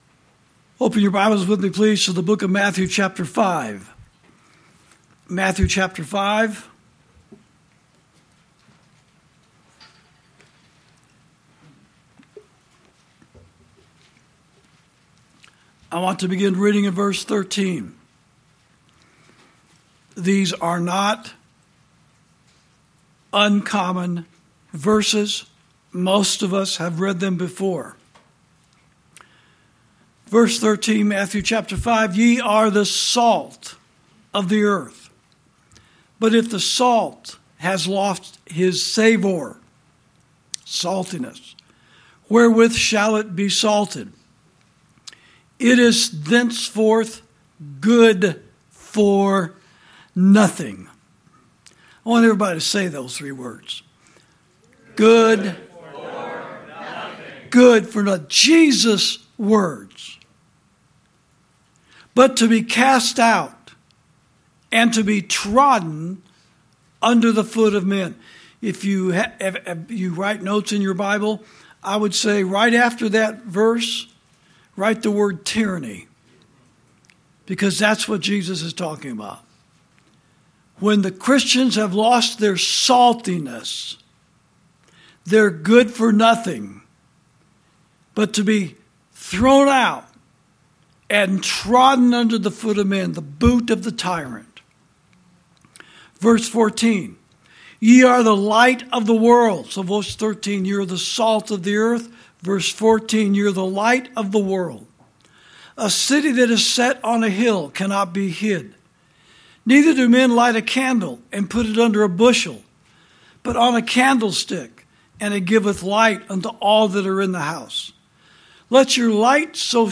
Sermons > When Christians Become "Good For Nothing"